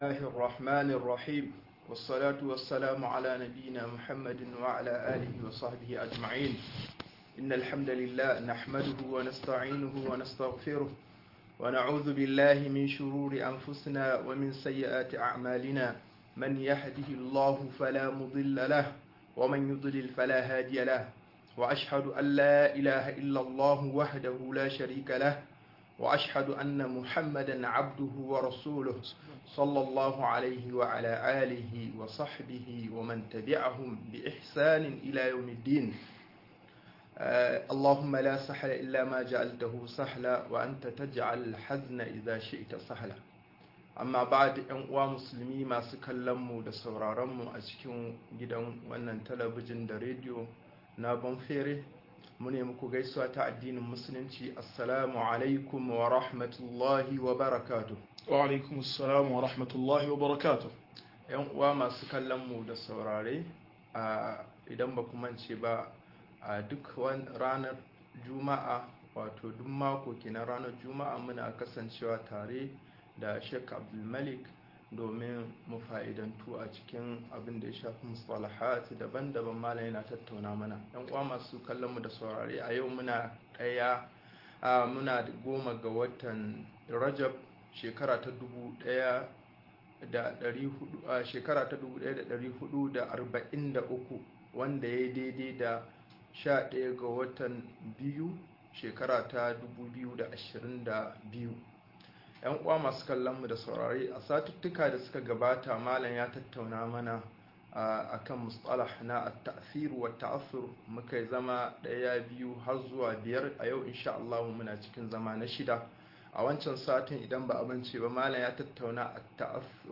Yin tasiri da tasirantuwa-06 - MUHADARA